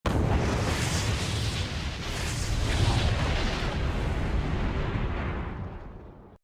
Rocketlauncher.ogg